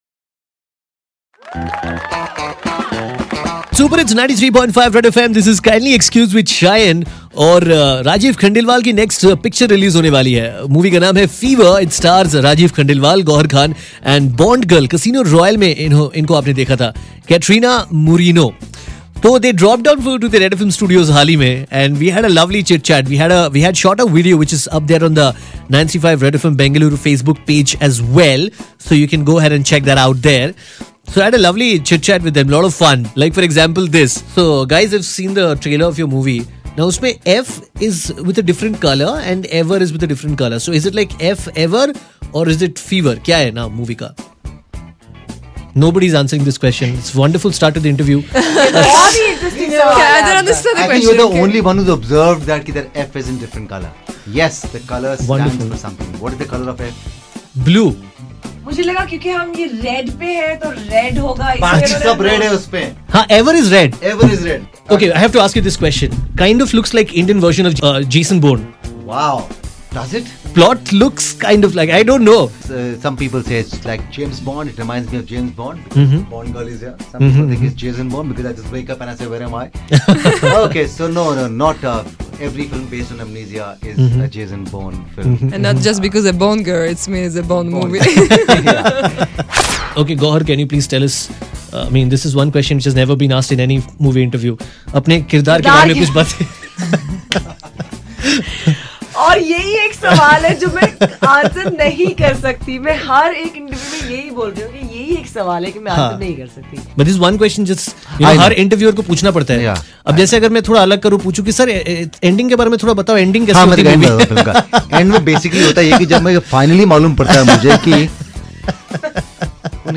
in the studio...